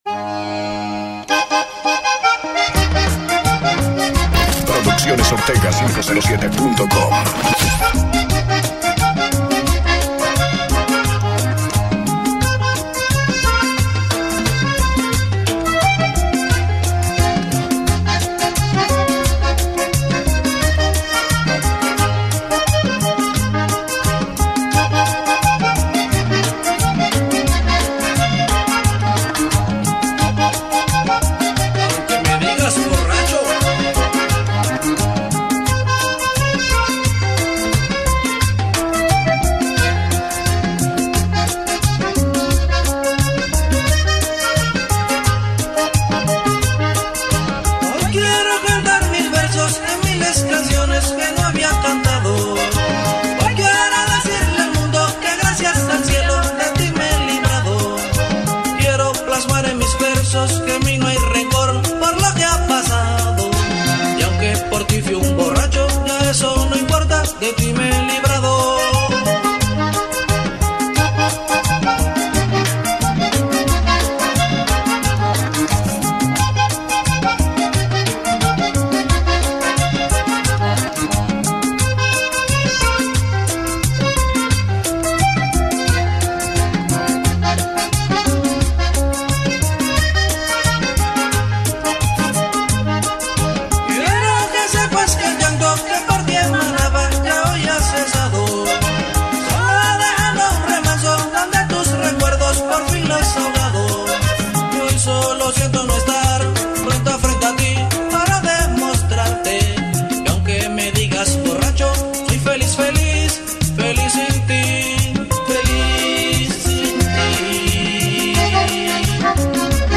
Dic 28, 2025 | Mixes, Típico 507